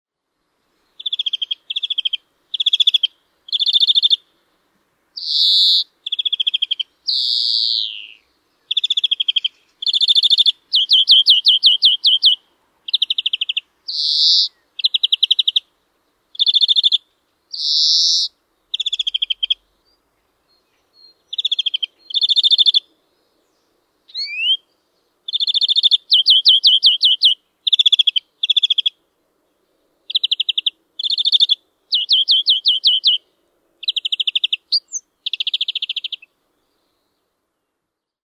Viherpeippo
Laulu: Kaksi laulutyyppiä. Yksinkertaisempi on pitkä ryystävä säe, jota koiras toistelee laululennosta tai näkyvältä paikalta, esimerkiksi katajan latvasta. Monipuolisempi laulu koostuu liverryksistä ja vihellyksistä.